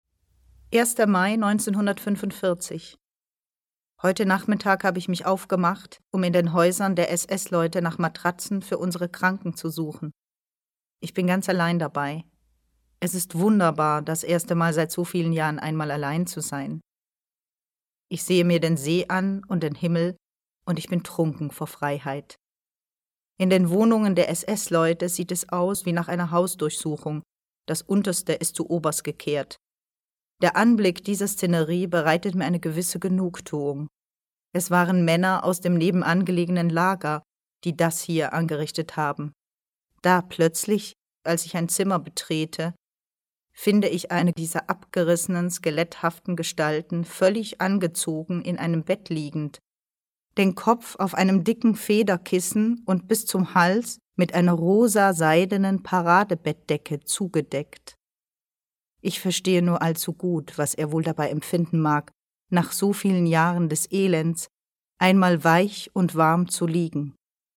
sehr variabel, dunkel, sonor, souverän, markant
Mittel plus (35-65)
Live Action